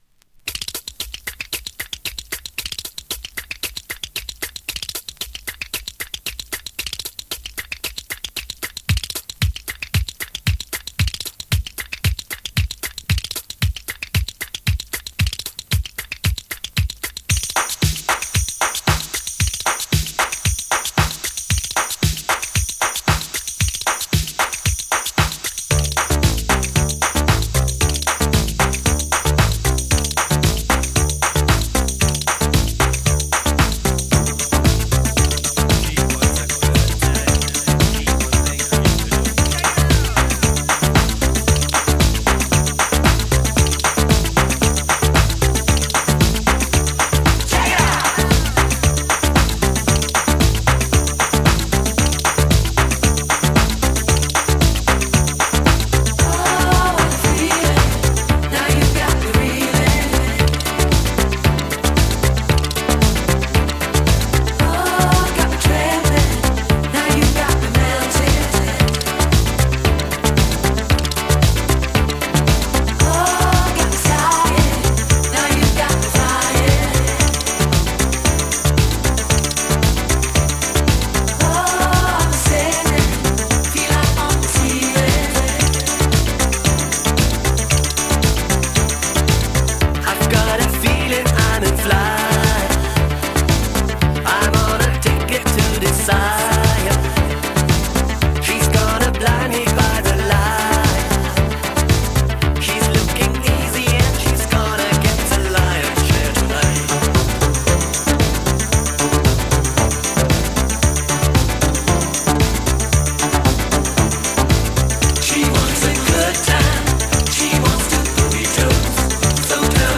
DISCO
German Synthe Boogie !! 80'sガラージュ古…